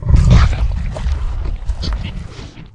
flesh_eat_1.ogg